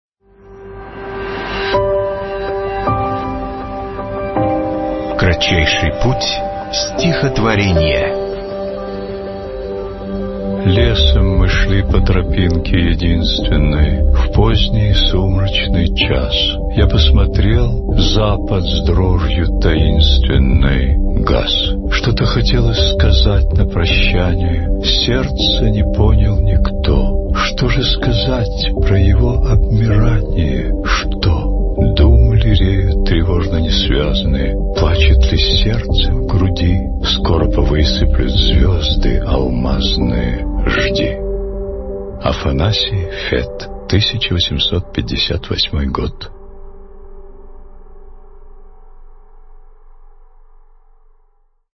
1. «Афанасий Фет – Лесом мы шли по тропинке единственной (читает Николай Мартон)» /
afanasij-fet-lesom-my-shli-po-tropinke-edinstvennoj-chitaet-nikolaj-marton